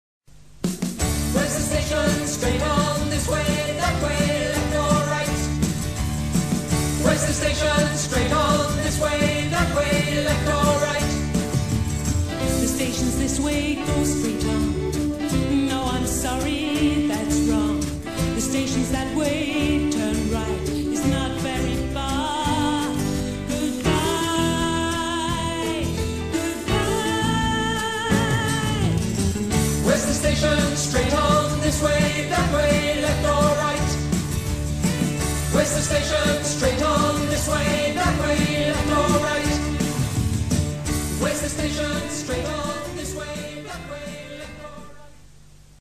con esta canción no comercial